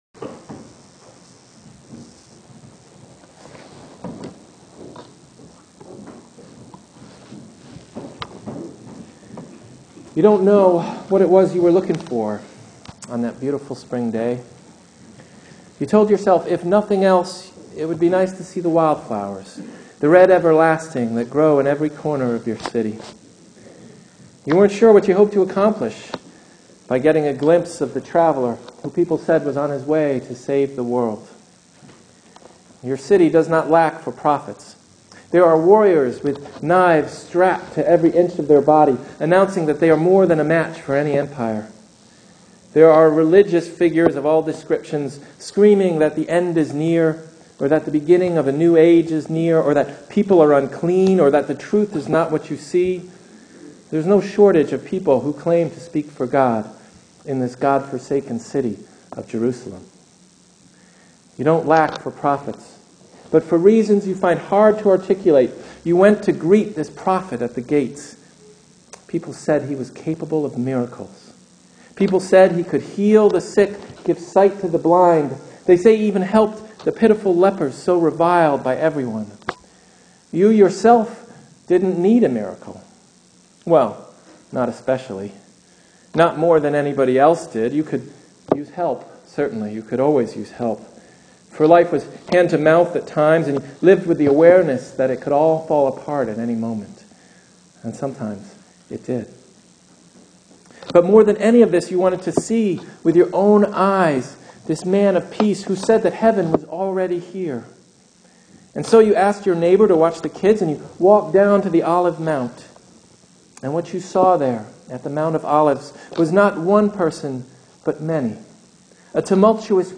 Easter sermon (audio and text)
easter-sermon-bjd.mp3